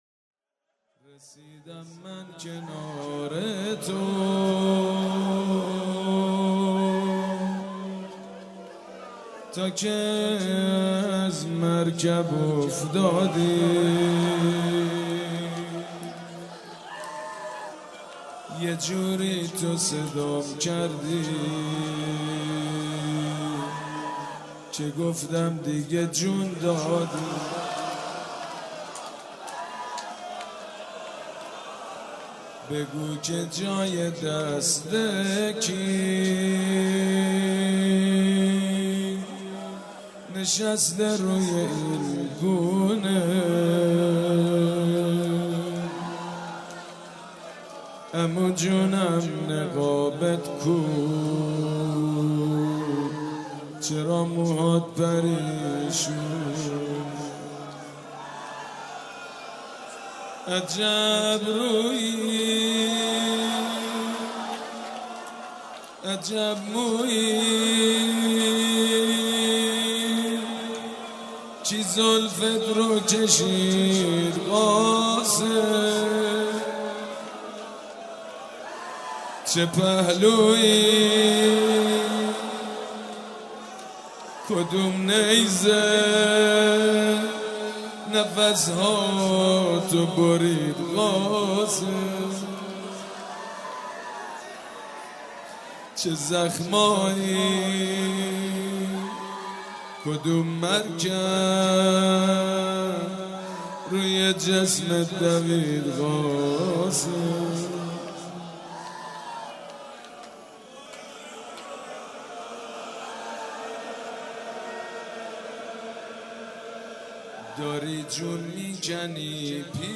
شب ششم محرم الحرام‌ جمعه ۱6 مهرماه ۱۳۹۵ هيئت ريحانة الحسين(س)
سبک اثــر روضه مداح حاج سید مجید بنی فاطمه